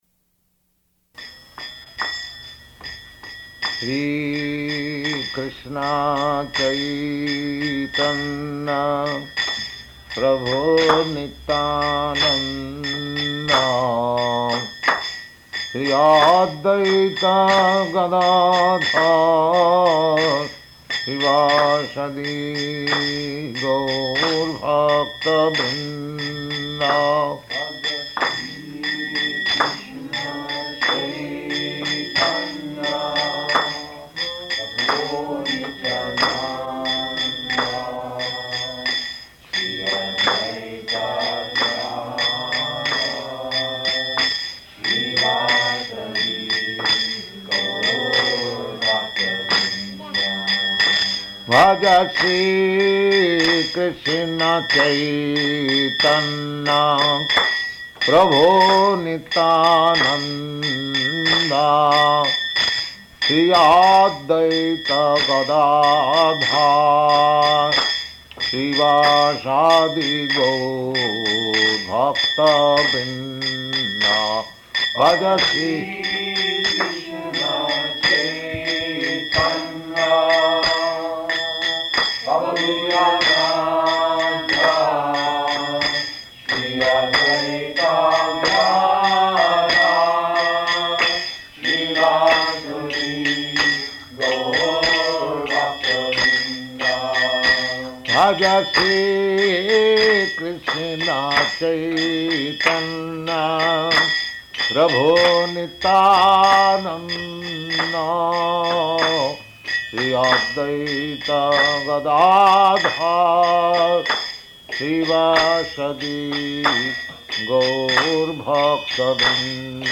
Bhagavad-gītā 2.8-12 --:-- --:-- Type: Bhagavad-gita Dated: November 27th 1968 Location: Los Angeles Audio file: 681127BG-LOS_ANGELES.mp3 Prabhupāda: [ kīrtana ] [ prema-dhvani ] Thank you very much.